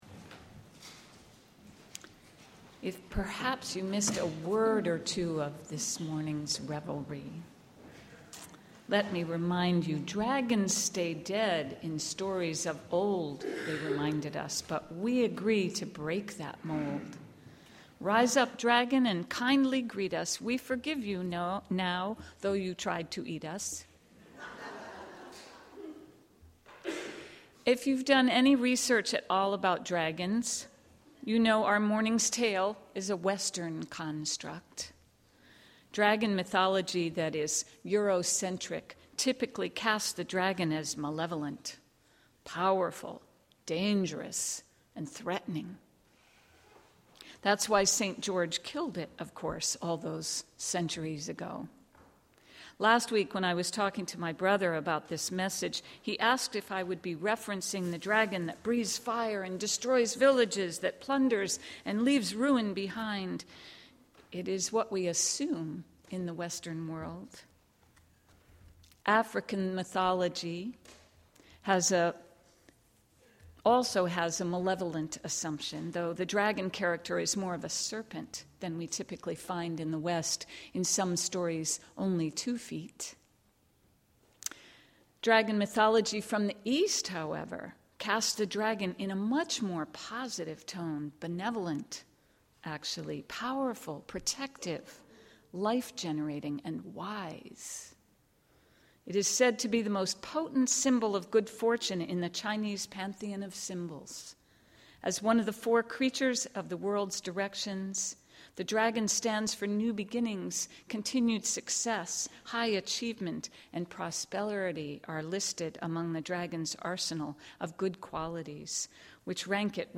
in which our children and youth share a wonderful holiday story with us to light up our sanctuary with fun and energy — AND we celebrate the season (and end our service) with an Instant Choir singing the Hallelujah chorus!